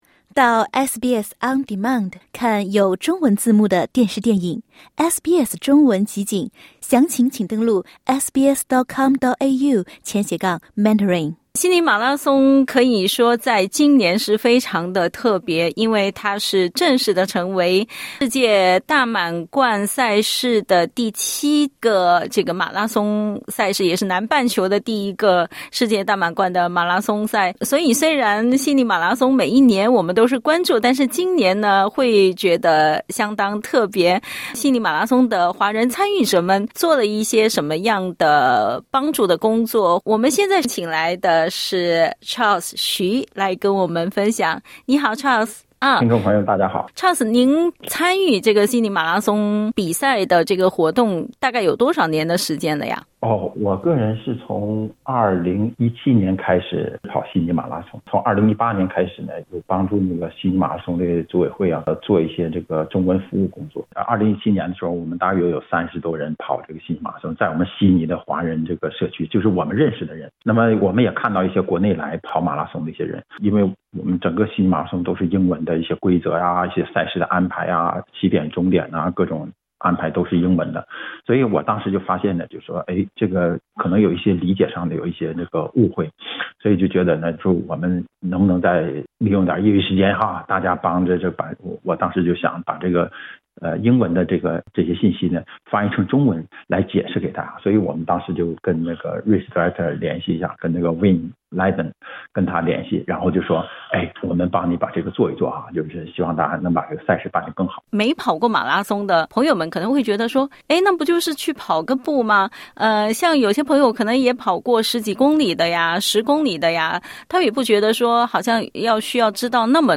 （点击音频收听详细采访）